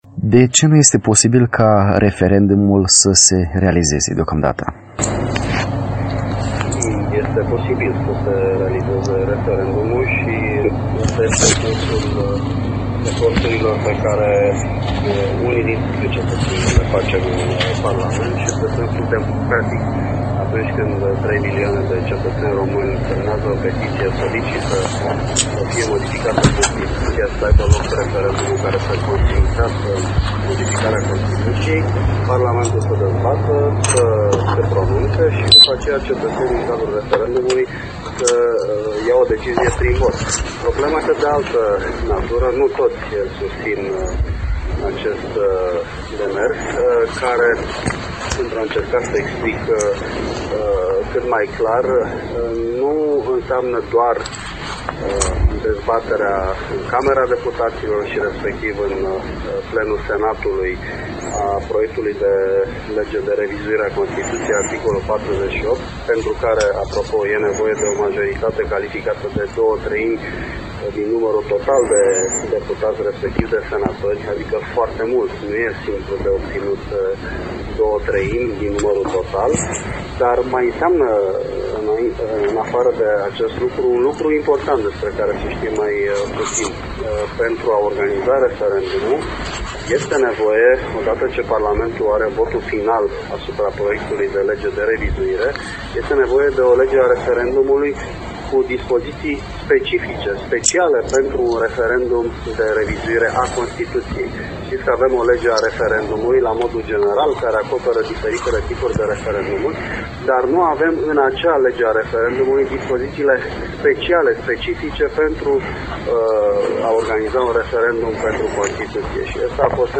Înregistarea este una defectuoasă.